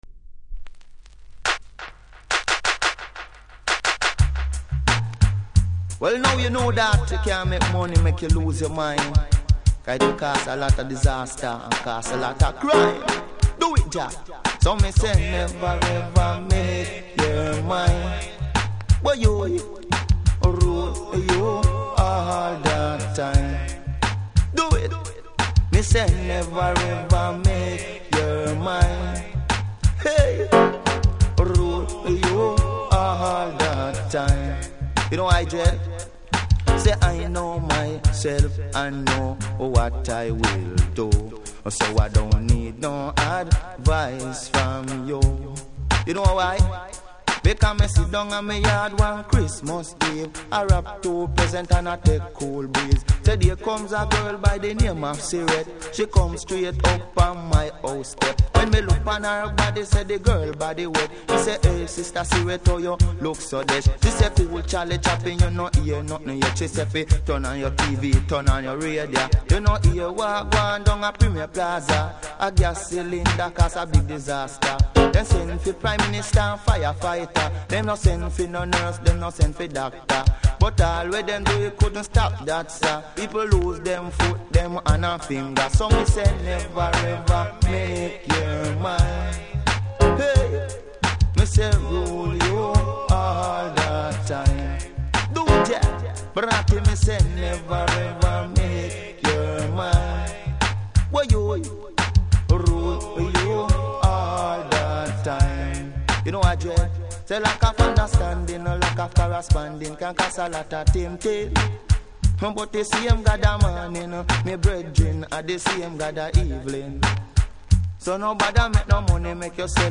所々ノイズありますので試聴で確認下さい。